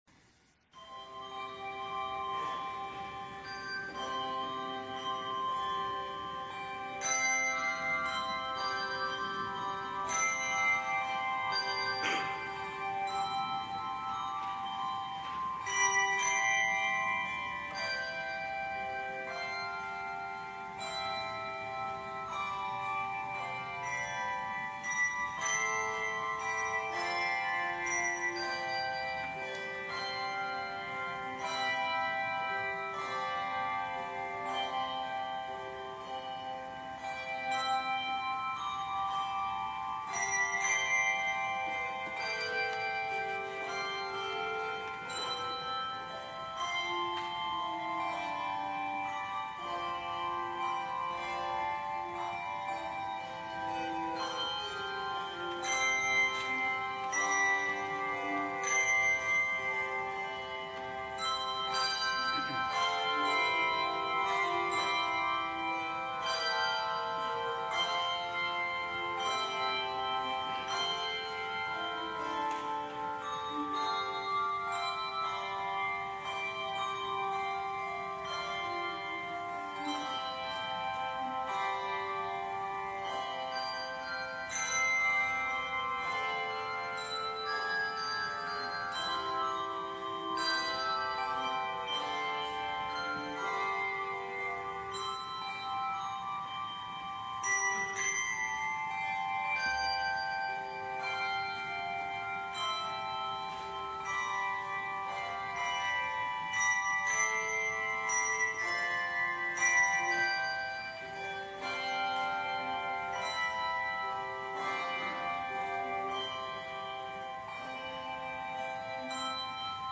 The Snow Lay on the Ground Douglas Wagner Second Reformed Bell Choir
Handbell Music